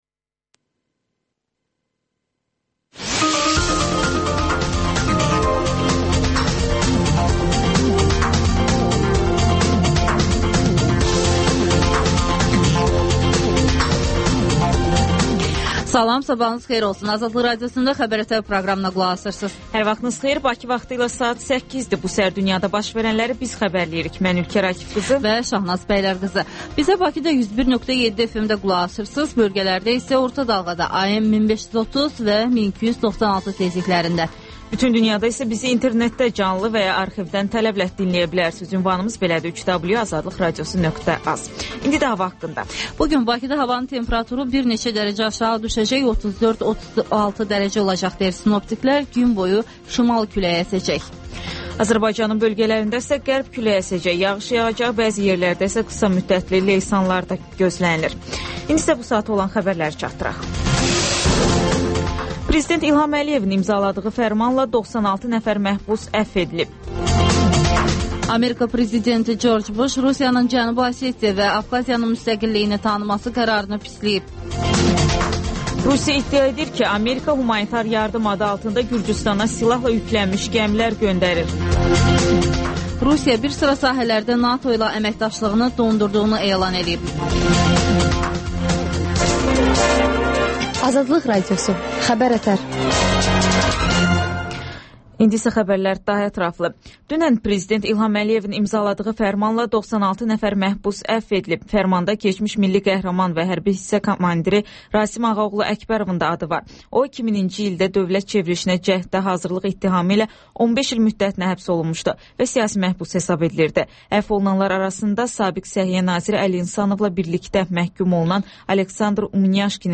Xəbərlər, müsahibələr